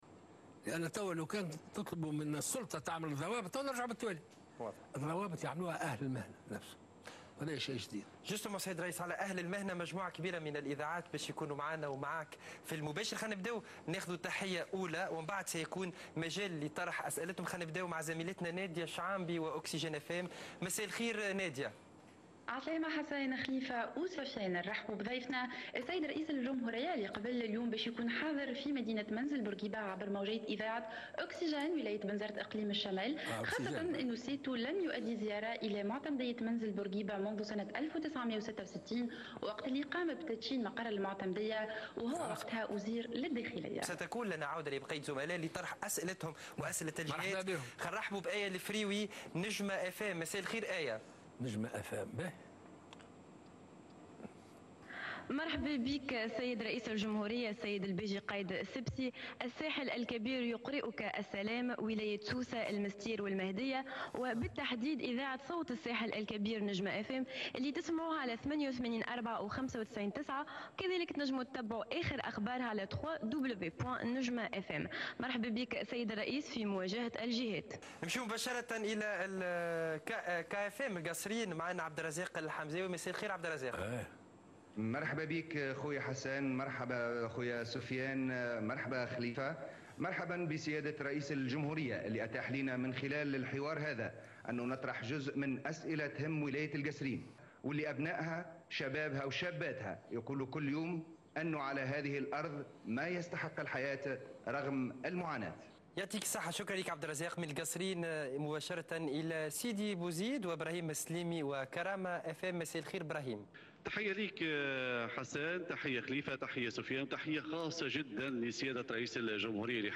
وقال رئيس الجمهورية، الباجي قايد السبسي، في حوارخاص مع قناة نسمة أن رئيس الولايات المتحدة الأمريكية دونالد ترامب دعاه لمواصلة التحاور في واشنطن.